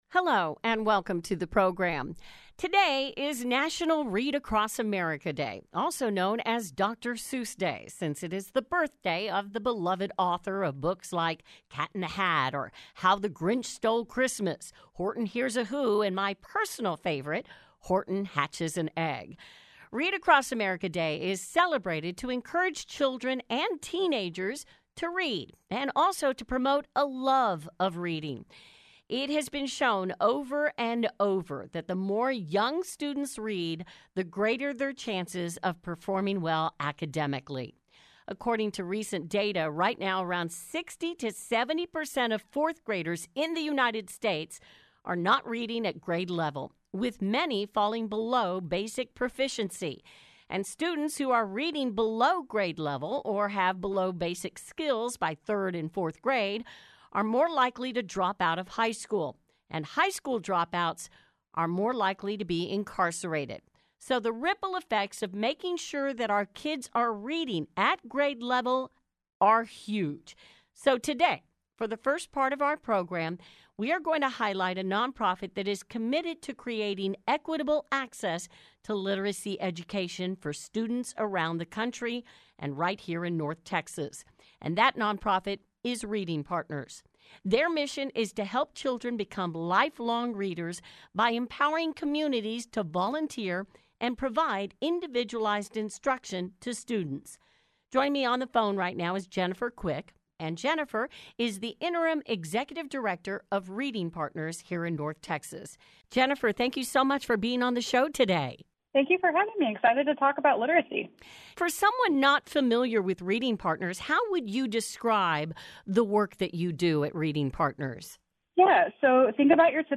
iHeartRadio Interview with Reading Partners North Texas - Reading Partners
Originally aired on iHeartRadio stations in the Dallas/Fort Worth area